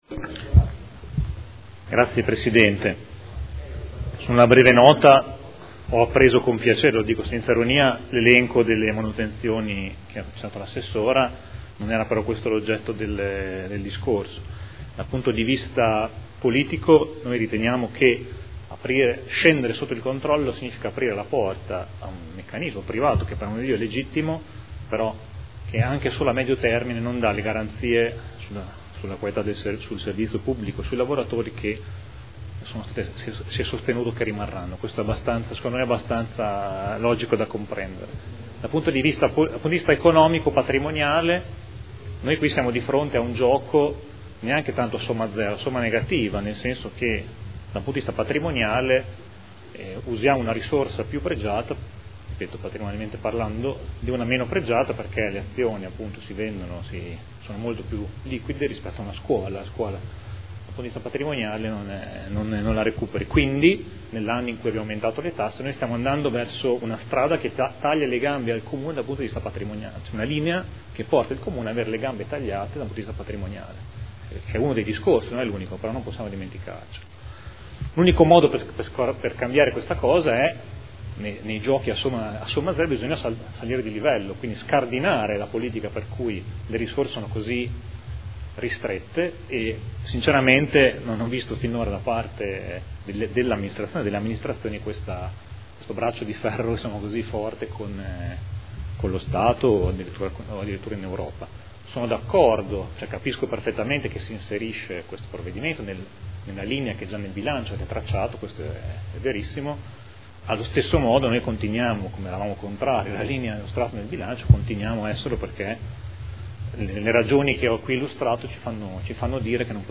Mario Bussetti — Sito Audio Consiglio Comunale